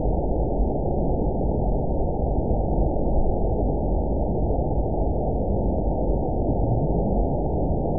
event 920548 date 03/29/24 time 03:34:20 GMT (1 month ago) score 7.64 location TSS-AB03 detected by nrw target species NRW annotations +NRW Spectrogram: Frequency (kHz) vs. Time (s) audio not available .wav